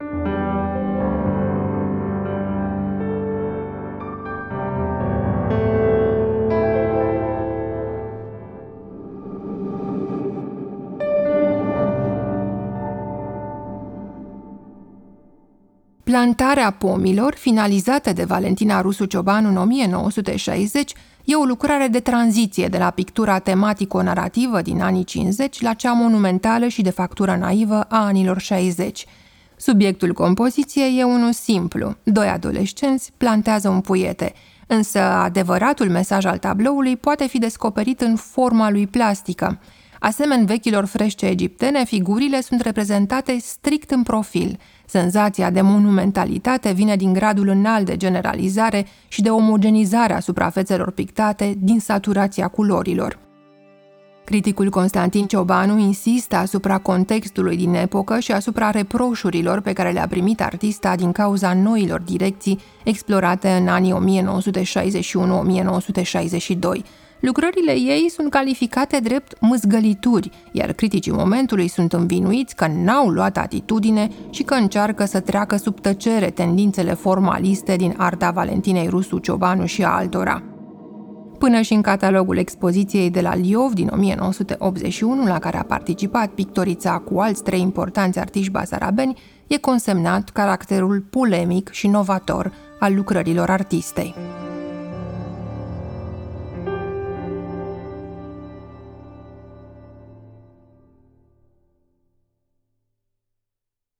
muzică: Rivulet Studio